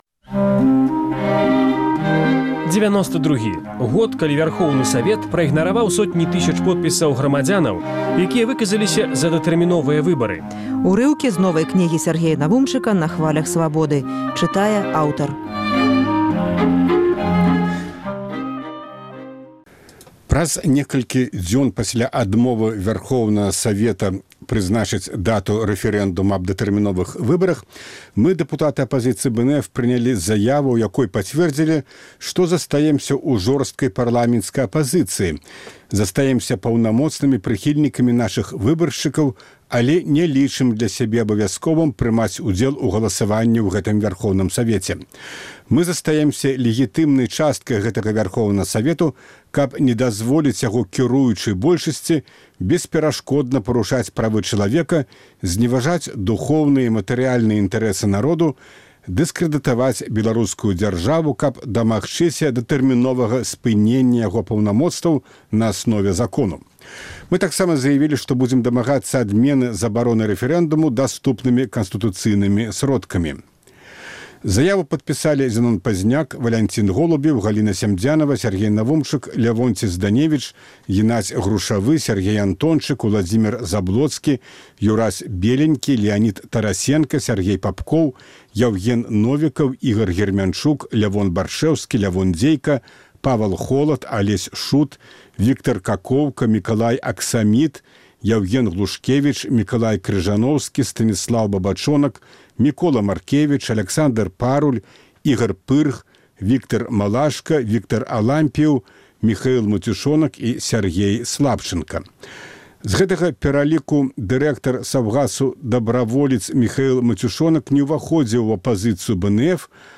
Апошнія фрагмэнты новай кнігі, прысьвечанай рэфэрэндуму аб новых выбарах, які не адбыўся. Чытае аўтар.